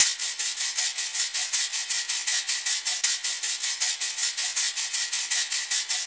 Crashes & Cymbals
SHAKER_POCKET_KILLER.wav